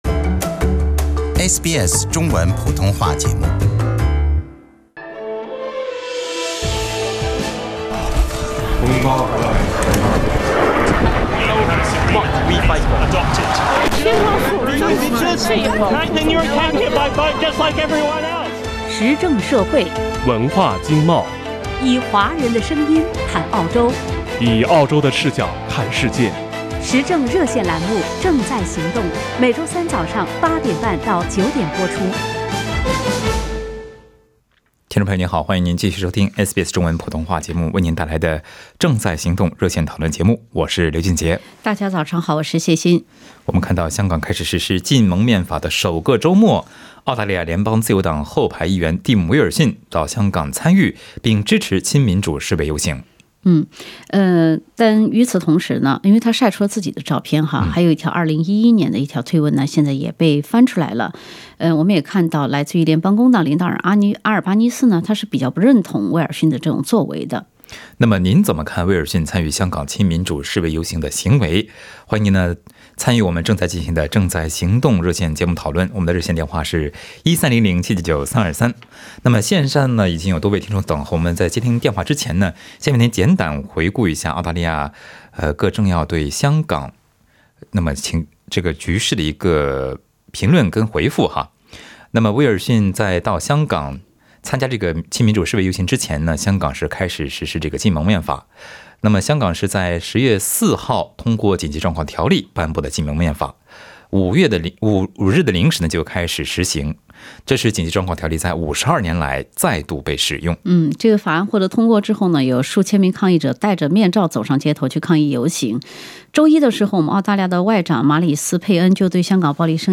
在本期《正在行动》热线节目中，听众朋友们就议员威尔逊参与香港亲民主示威游行的行为表达了各自的看法。